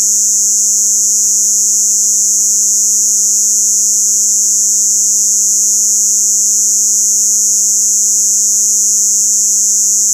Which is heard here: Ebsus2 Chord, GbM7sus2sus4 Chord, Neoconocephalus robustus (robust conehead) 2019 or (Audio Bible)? Neoconocephalus robustus (robust conehead) 2019